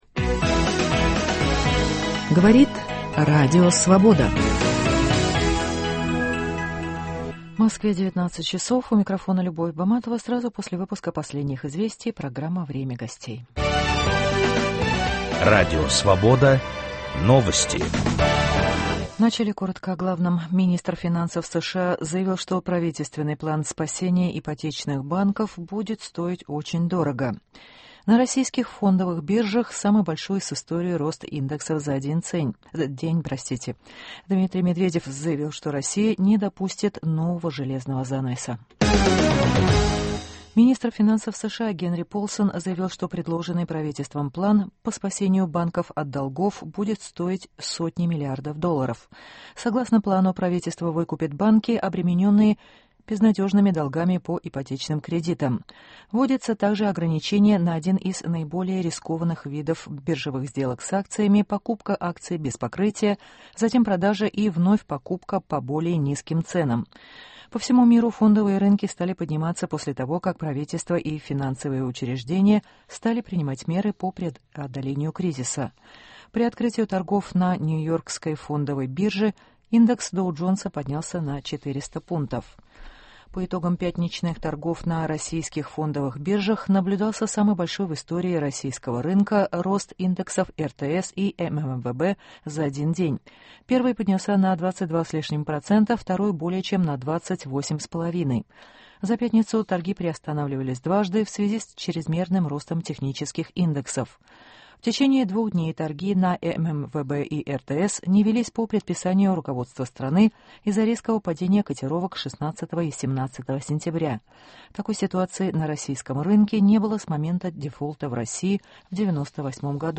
Плата за стабильность. О финансовом кризисе и его последствиях вместе со слушателями размышляет экономист Ирина Ясина